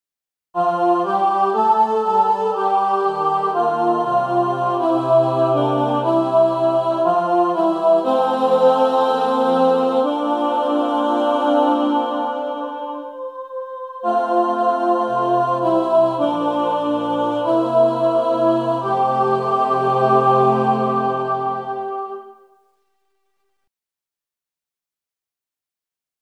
Key written in: F Major
Other part 1: